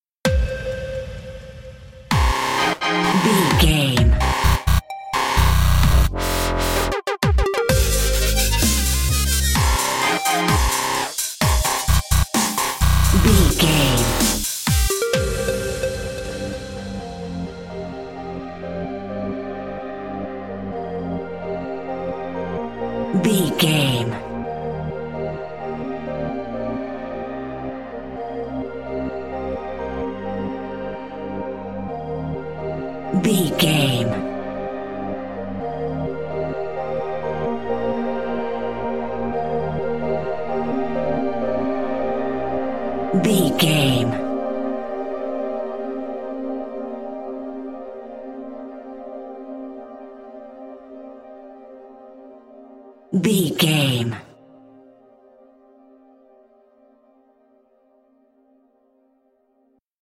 Epic / Action
Fast paced
Aeolian/Minor
aggressive
dark
intense
energetic
driving
synthesiser
drum machine
futuristic
breakbeat
synth leads
synth bass